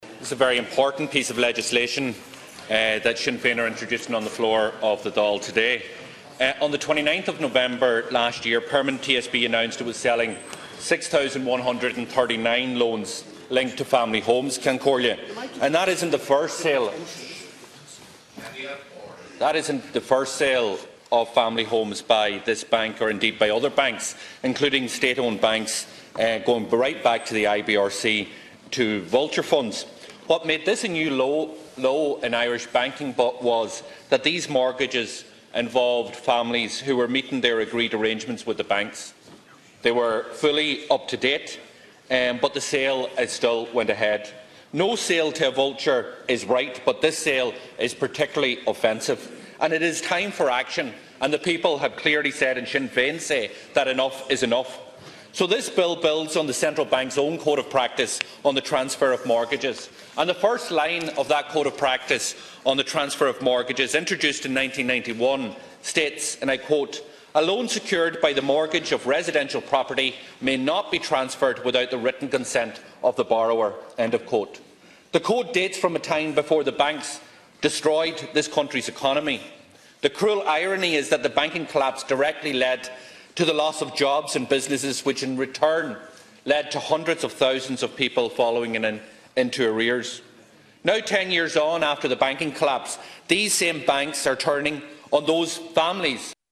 Speaking in the Dail, Deputy Pearse Doherty says the banking collapse led to homeowners being in arrears and a loss of jobs and claims those banks are now turning on the same people: